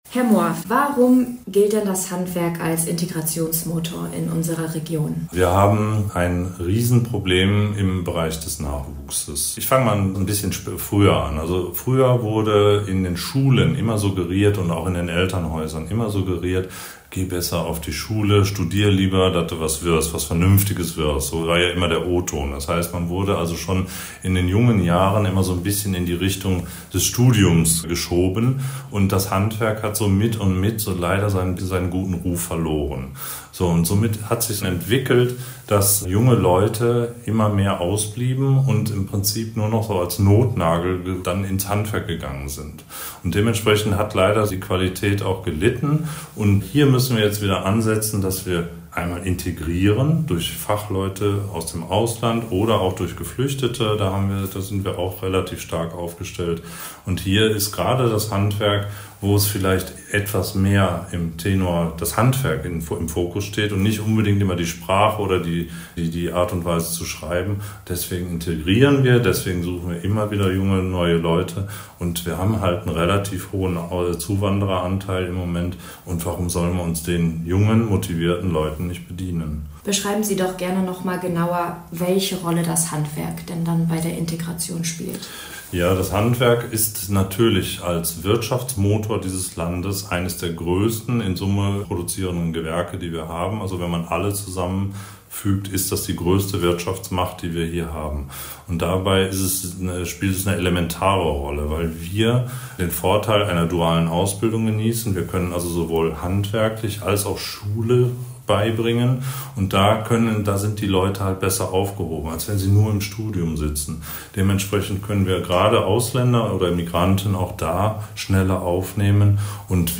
Radiobeiträge: Integration im Handwerk